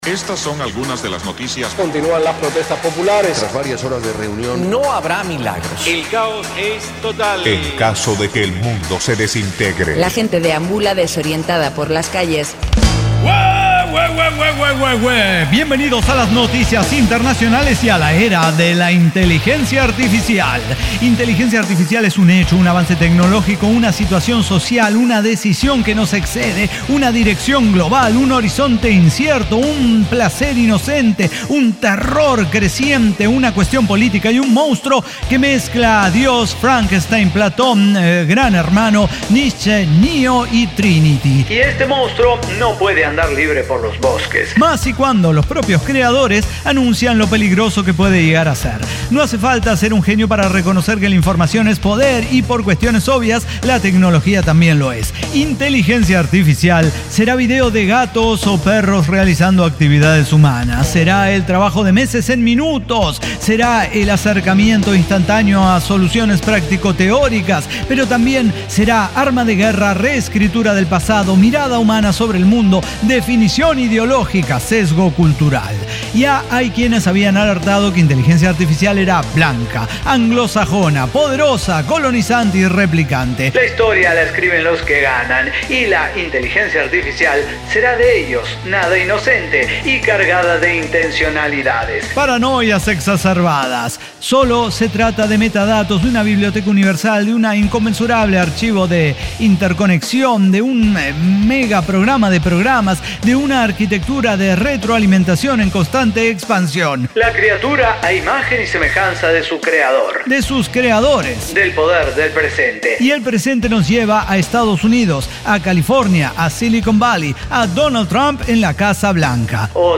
Diseño, guionado, música, edición y voces son de nuestra completa intervención humana.